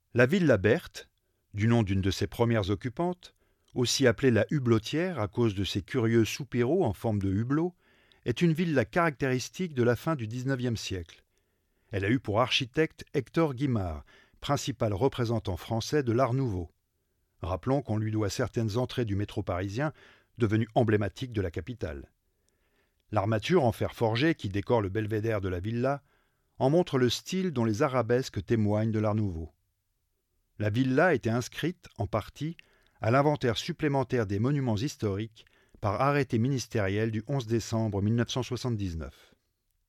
Bandes-son
40 - 60 ans - Baryton